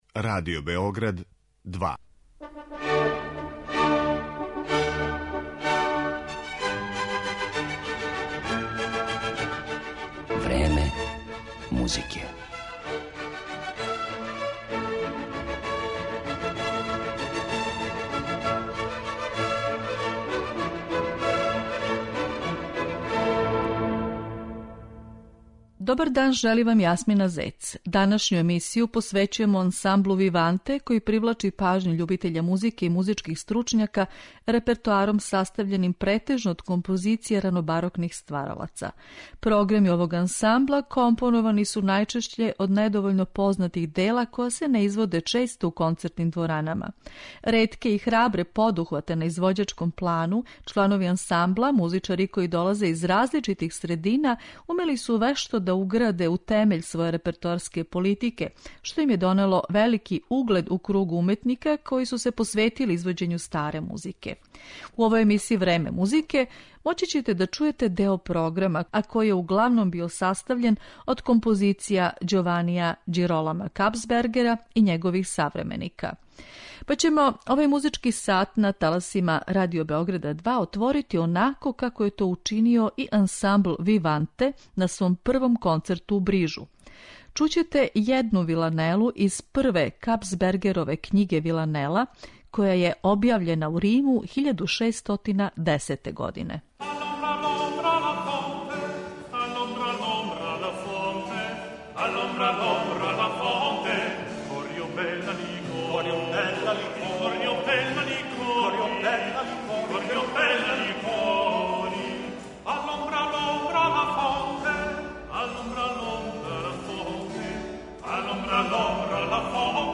Ансамбл "Виванте" је ретке и храбре подухвате на плану репертоара, који је углавном компонован од дела која се не изводе често у концертним дворанама, успео вешто да угради у темеље своје извођачке политике, па није чудно што данас ужива велики углед међу ансамблима специјализованим за музику 17. и 18. века.